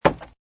woodwood2.mp3